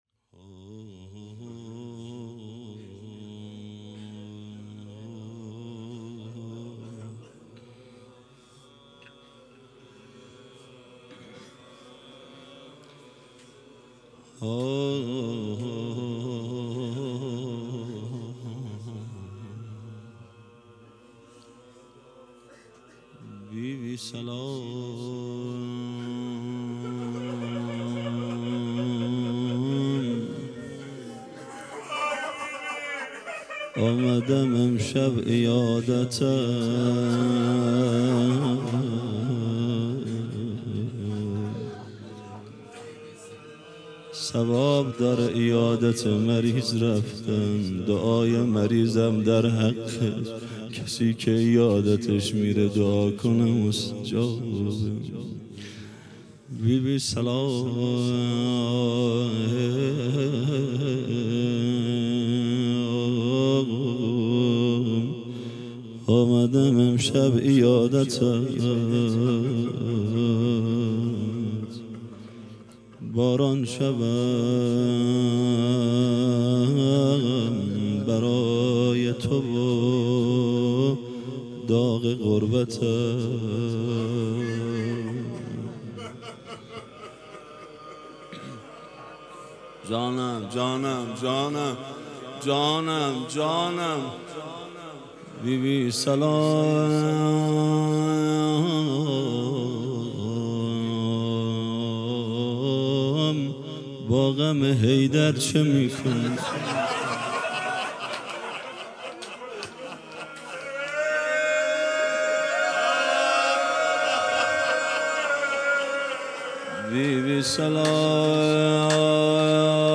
روضه ایام شهادت حضرت زهرا سلام الله علیها 1443 (ه ق)